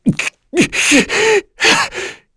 Esker-Vox_Sad2_kr.wav